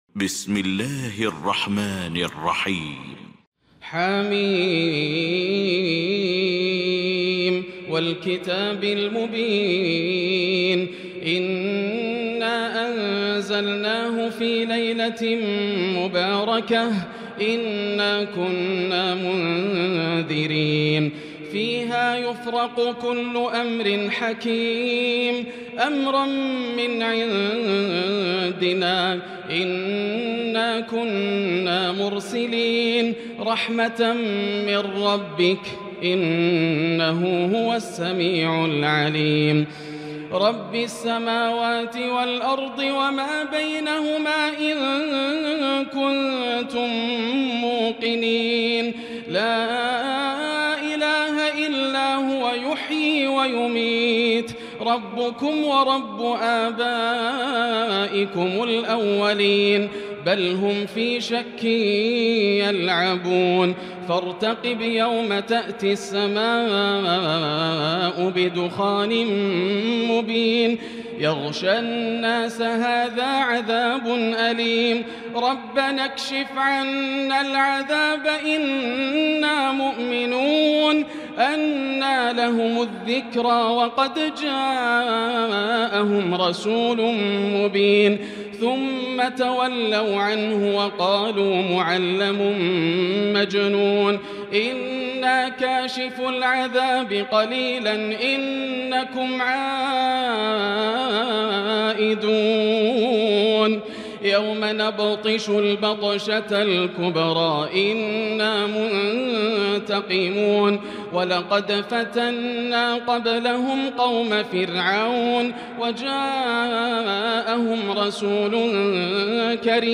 المكان: المسجد الحرام الشيخ: فضيلة الشيخ ياسر الدوسري فضيلة الشيخ ياسر الدوسري الدخان